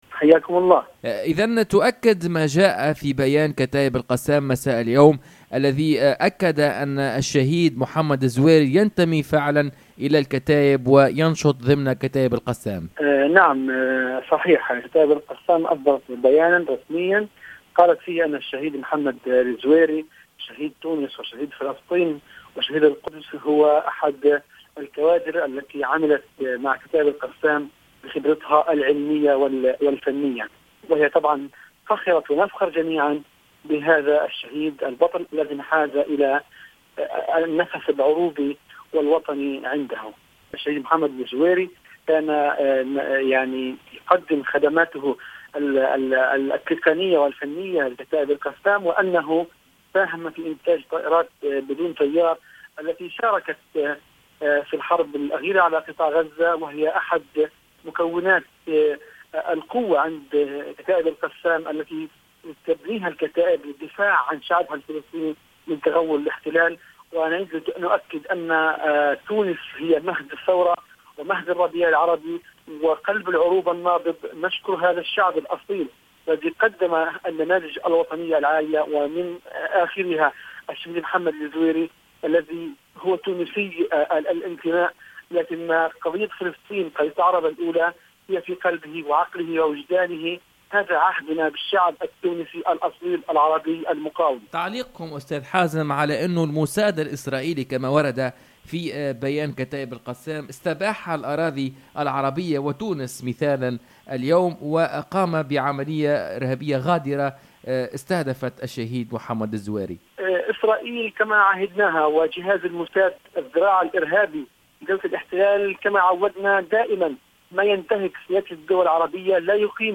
عبر الهاتف